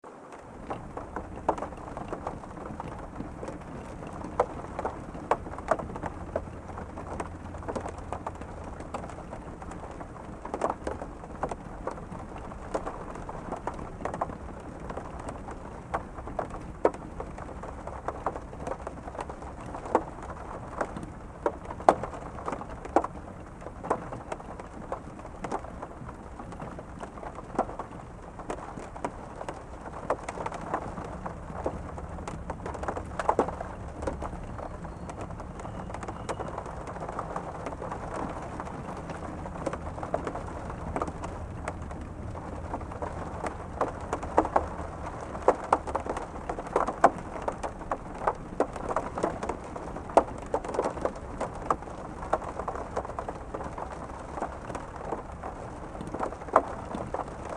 Gentle Rain Pattering on Window sound effects free download
Gentle Rain Pattering on Window on Chilly Night w/ Faint Sporadic Moaning Wind | Gentle Rain Sounds
Whatever it is, it's giving me a hint of an eerie (and for some reason, cozy) vibe.